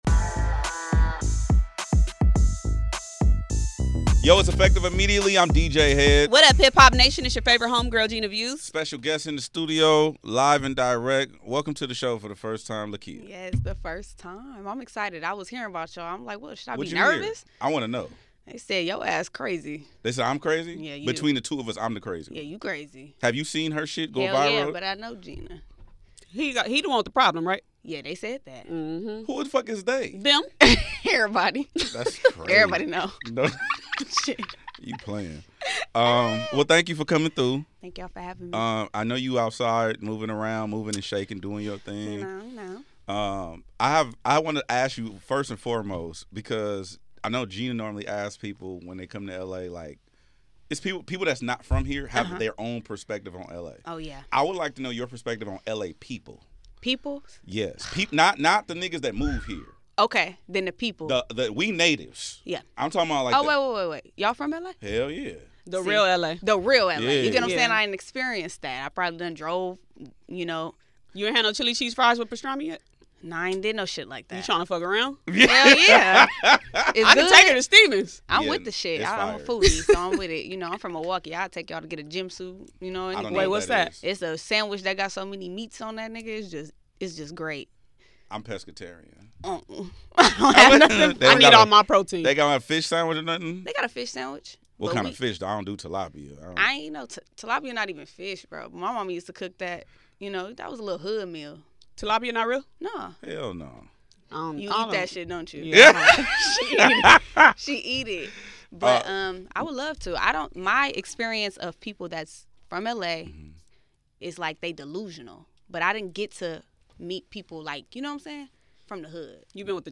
Effective Immediately is a nationally syndicated radio show and podcast that serves as the ultimate destination for cultural conversations, exclusive interviews, and relevant content. Hosted by radio and television veteran DJ Hed and new media superstar Gina Views, the show is dedicated to injecting integrity and authenticity back into the media landscape.